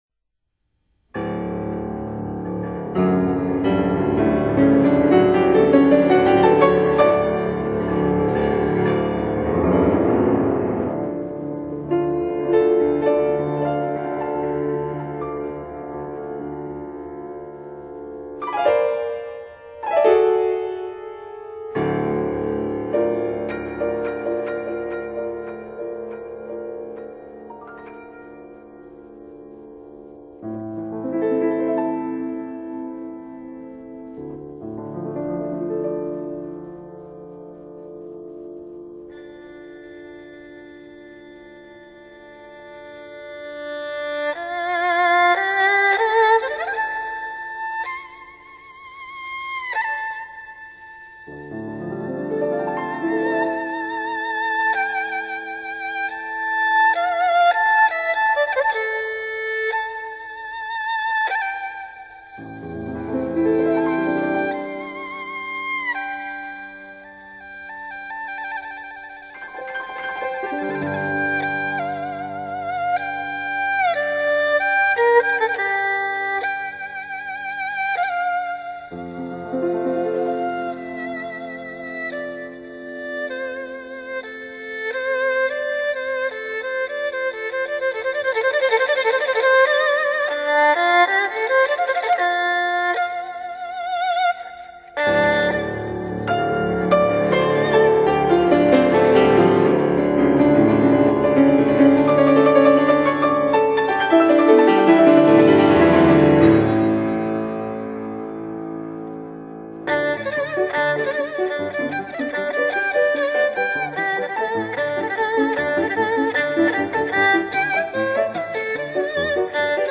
二胡独奏
钢琴伴奏
其旋律非常富有民族特色，且时代气息特别浓厚，情绪多变、形象丰富